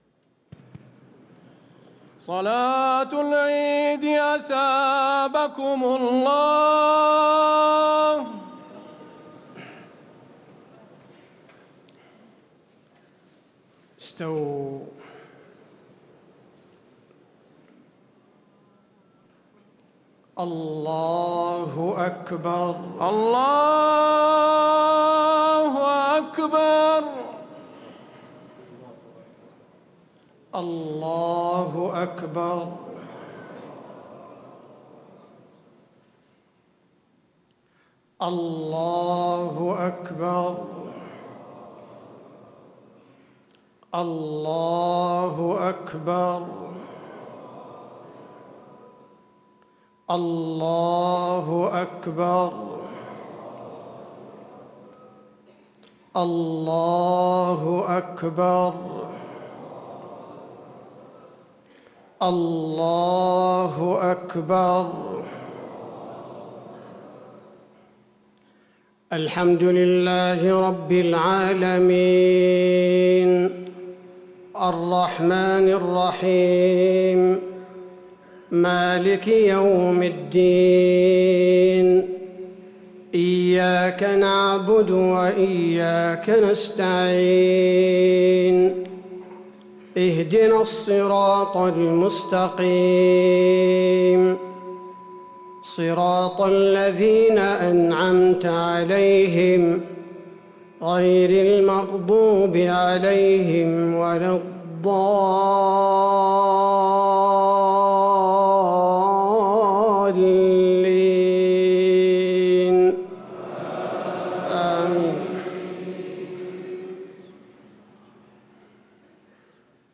صلاة عيد الفطر المبارك 1 شوال 1437هـ سورتي الضحى و الفيل > 1437 🕌 > الفروض - تلاوات الحرمين